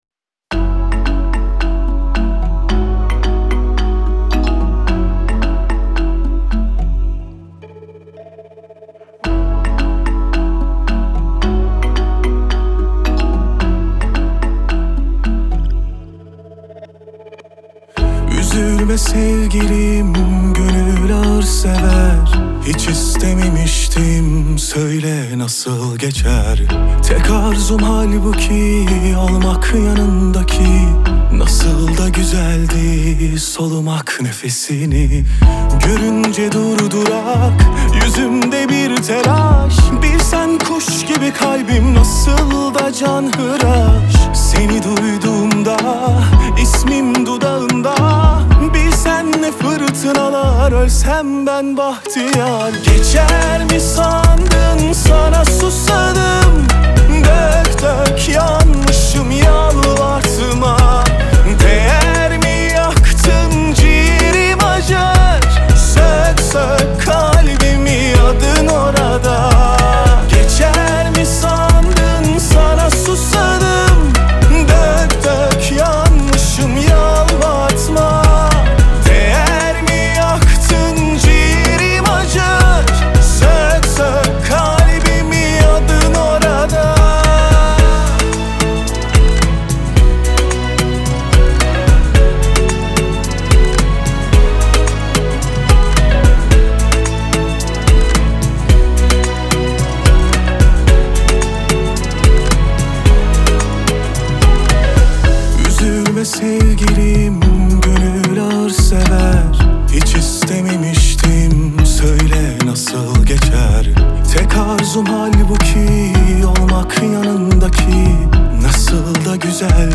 Трек размещён в разделе Турецкая музыка / Поп / 2022.